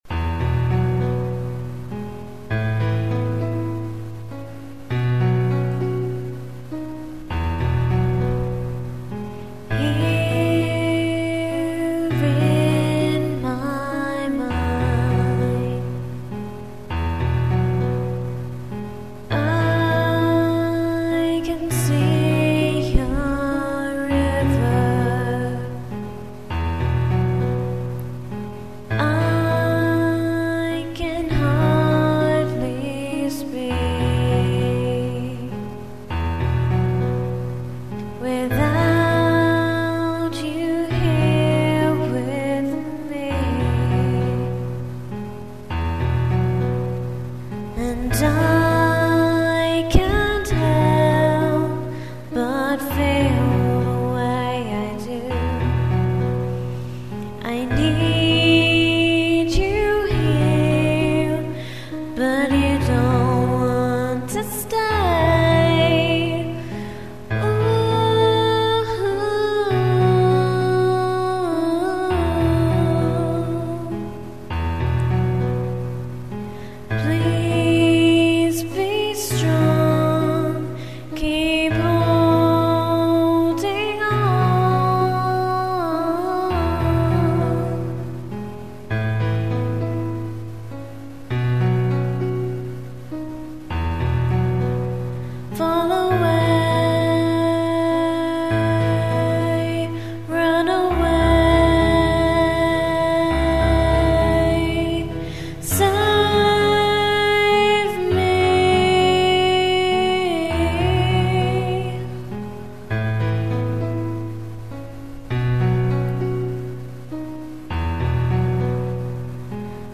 (Less Reverb)